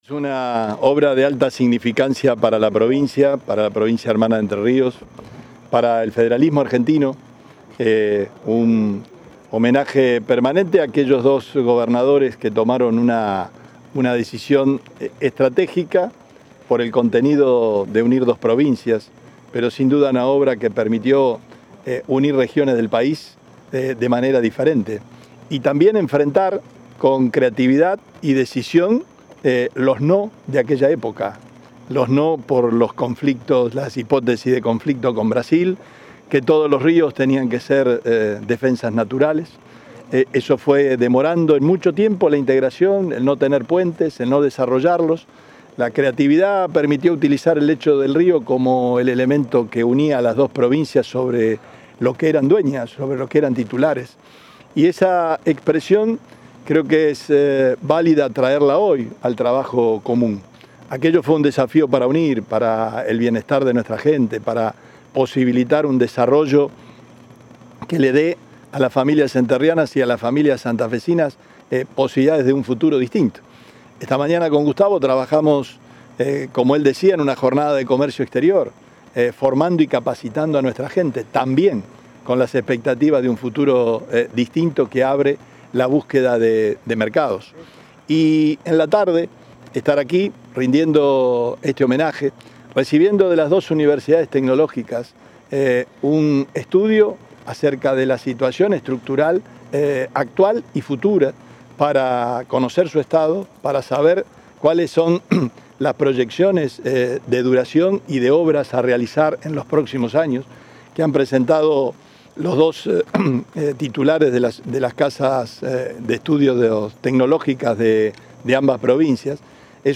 “Una muy buena manera de rendir homenaje, es resguardar una infraestructura de estas características y poner en valor a quienes realizaron aquella tarea”, afirmó el gobernador de Santa Fe, en el acto por un nuevo aniversario de la conexión vial.